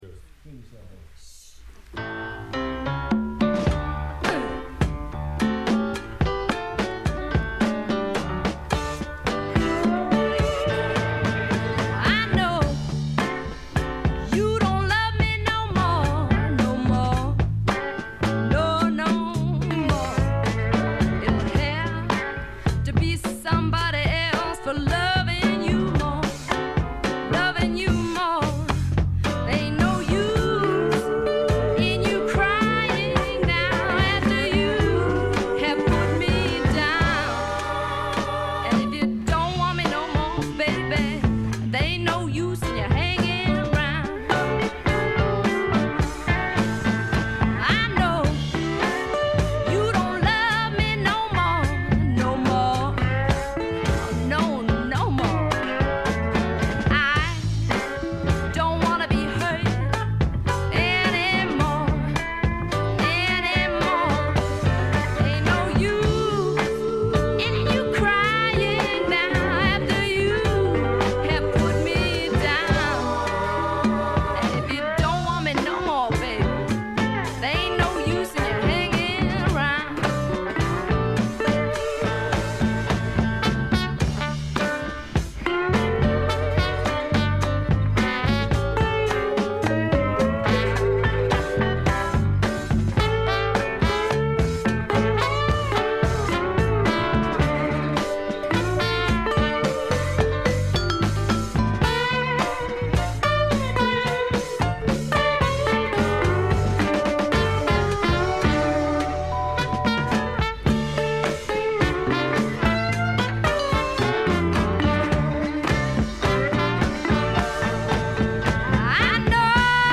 ウッドストック・べアズビル録音の名盤としても有名です。